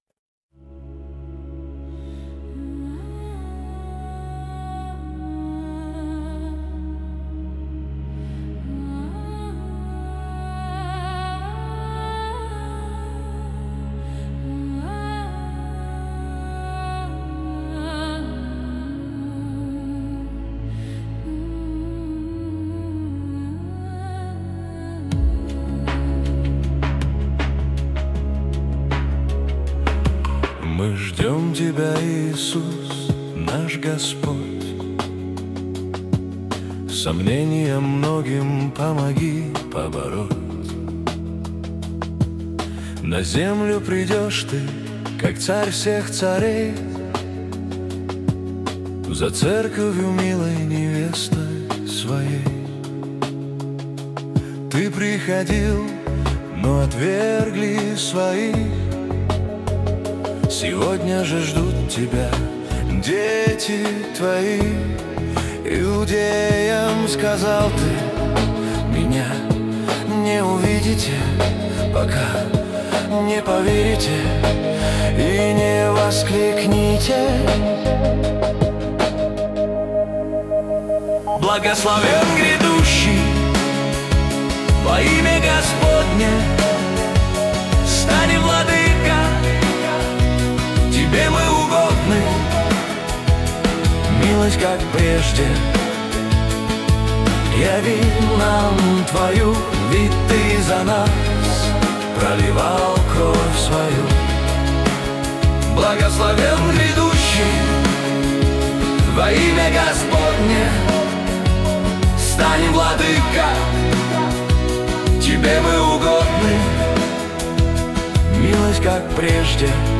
19 просмотров 148 прослушиваний 5 скачиваний BPM: 79